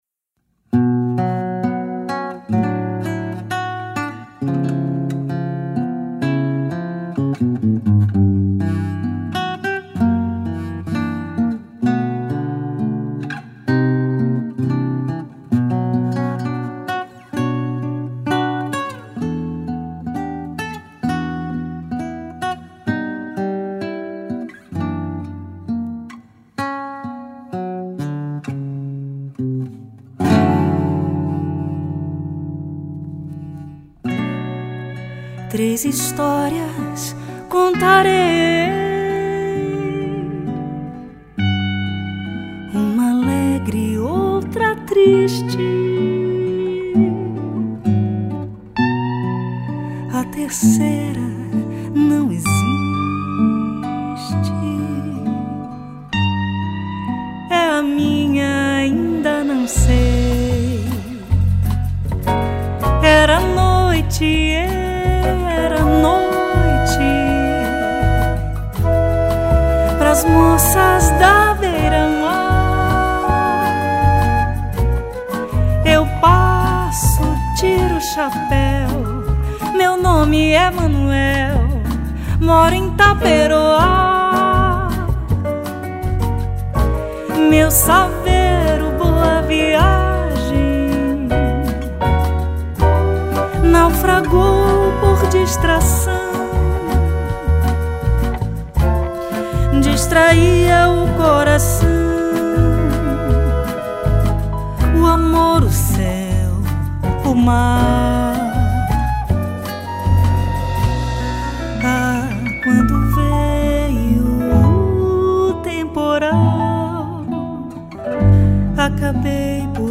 2604   06:19:00   Faixa: 6    Mpb